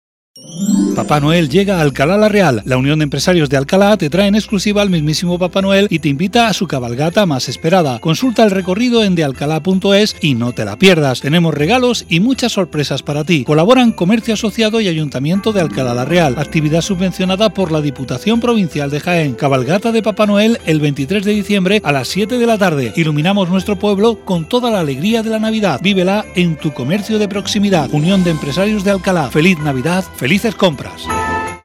Spot publicitario cabalgata en radio Onda Cero Alcalá Sierra Sur durante toda la Navidad